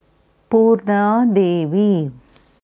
Sanskrit Purna korrekte Aussprache anhören
Hier kannst du hören, wie man ganz korrekt das Wort Purna ausspricht.